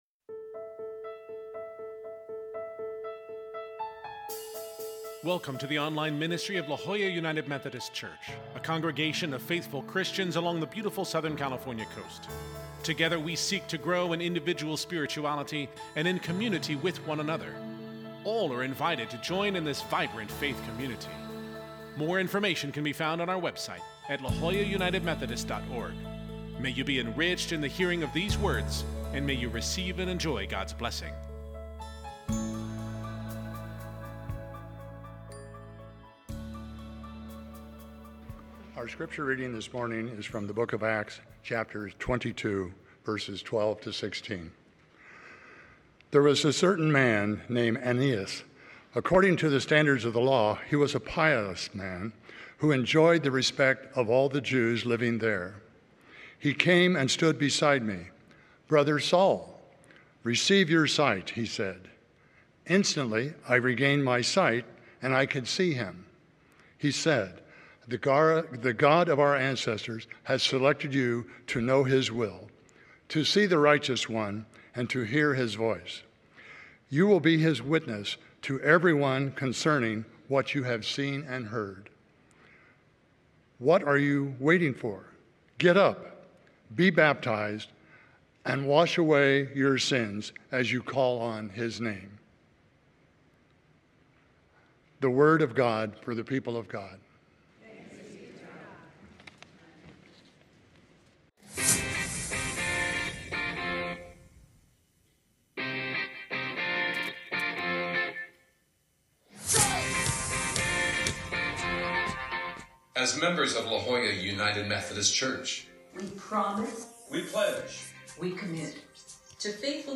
Scripture: Acts 22:12-16 (CEB) worship bulletin Sermon Note Share this: Print (Opens in new window) Print Share on X (Opens in new window) X Share on Facebook (Opens in new window) Facebook